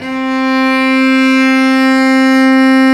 Index of /90_sSampleCDs/Roland LCDP05 Solo Strings/STR_Vc Arco Solo/STR_Vc Arco p nv